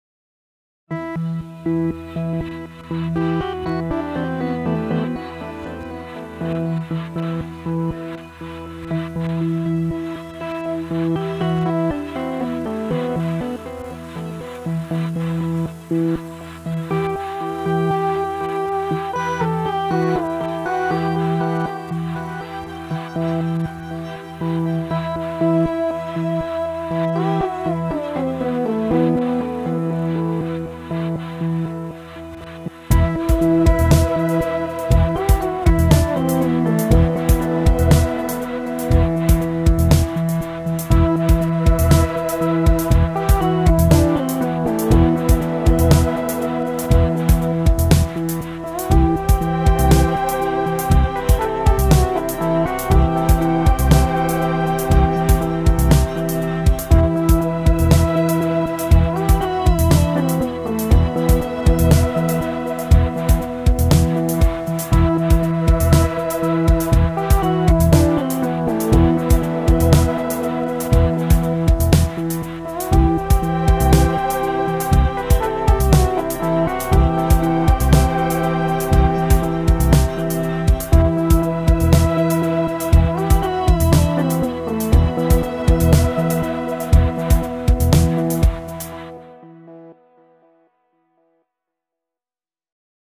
tema dizi müziği, duygusal hüzünlü heyecan fon müziği.